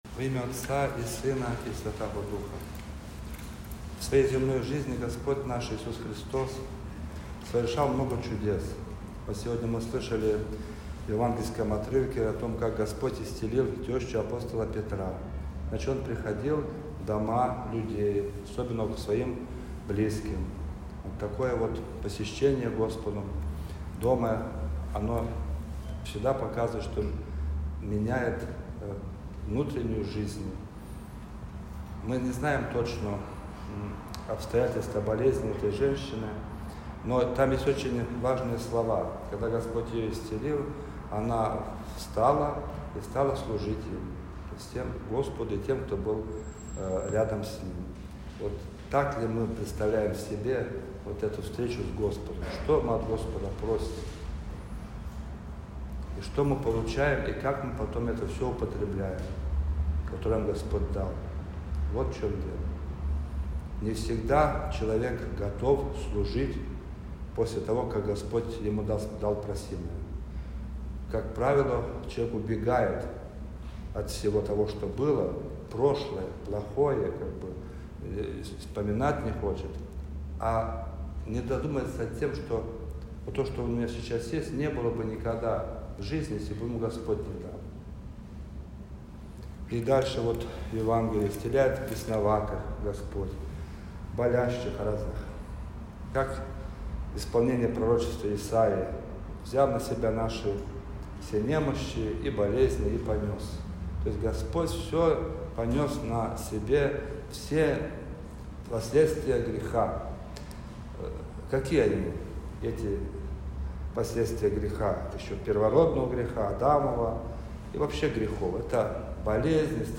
Из духовной беседы настоятеля с прихожанами о радости, которую дает Господь